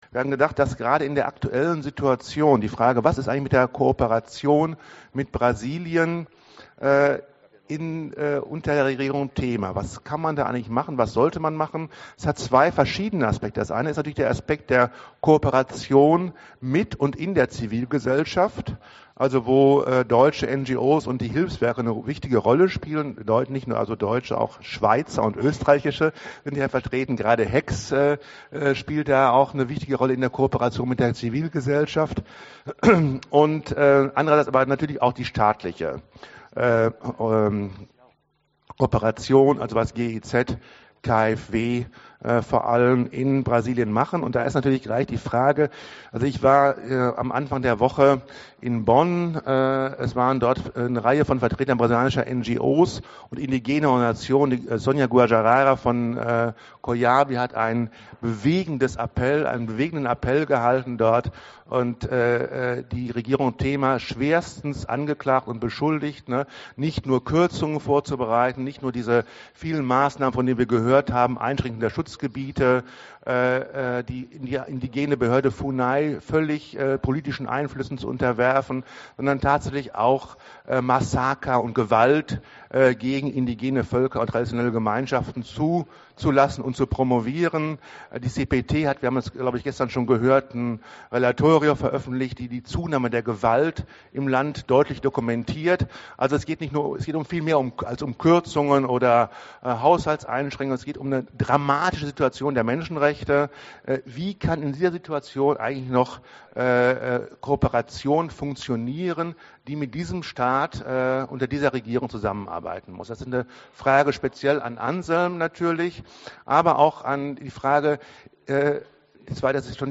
Diskussion:
Audio-Aufnahme des Fish-Bowls | Download (mp3)